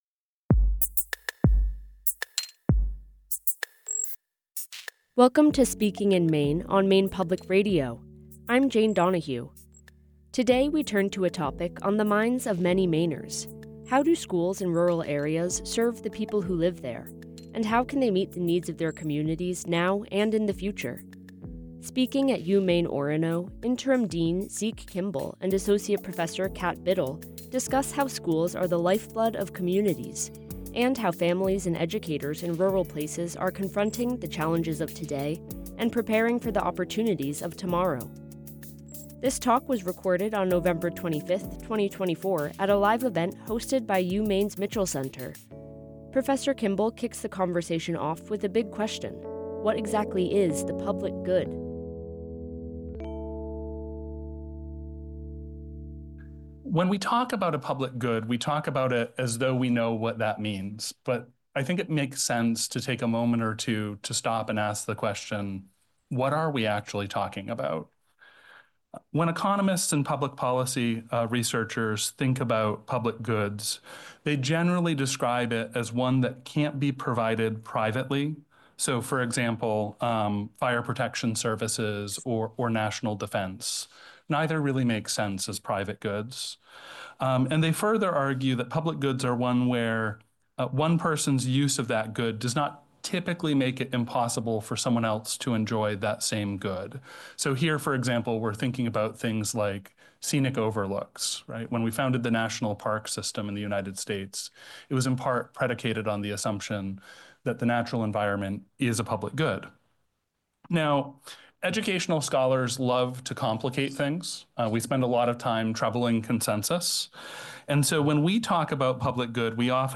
One of the many public affairs lecture series Maine Public Radio presents weekdays in the 2:00 pm Public Affairs Programs block. In partnership with many civic minding organizations and educational institutions from all around the state, Speaking in Maine features diverse speakers addressing many of the important issues of our day.